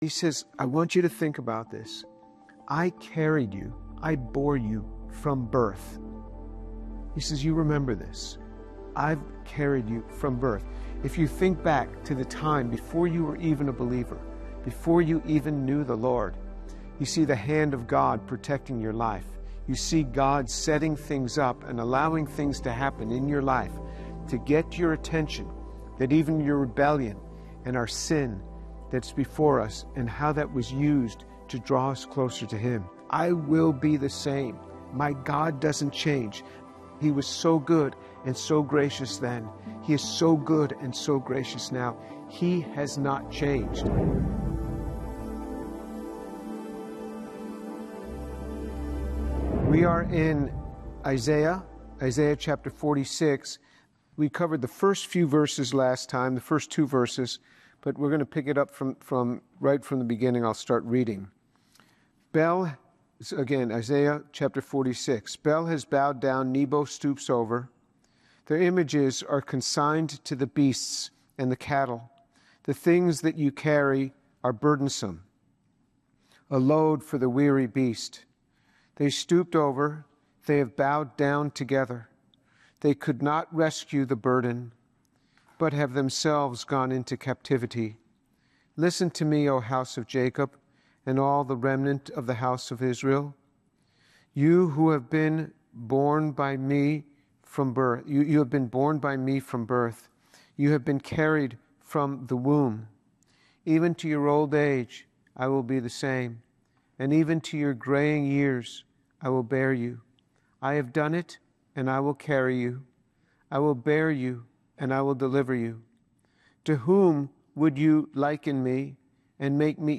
In his teaching on Isaiah 46, Dr. James Tour contrasts powerless idols with God, who carries His people from birth to old age, proving His sovereignty by fulfilling prophecy and bringing salvation. He challenges listeners—especially the young—to trust God’s unchanging nature and boldly serve Him early in life rather than waiting.